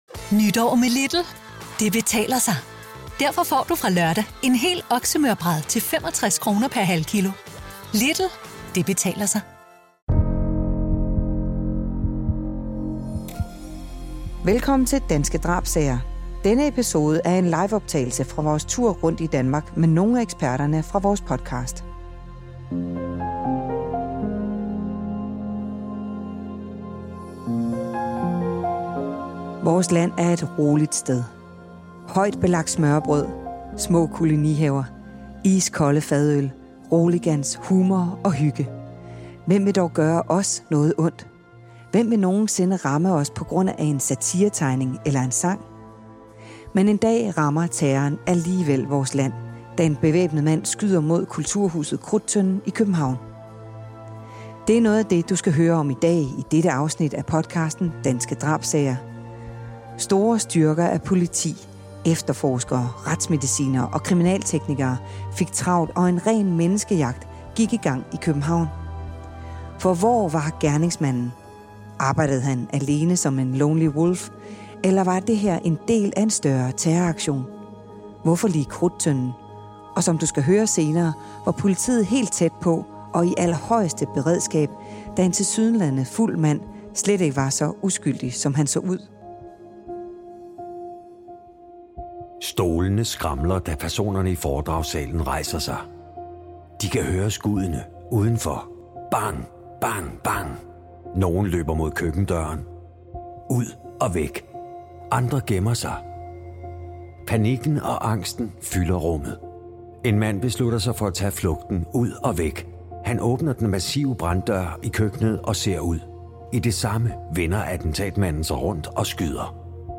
Live-podcast: Da terroren ramte Danmark ~ Danske Drabssager Podcast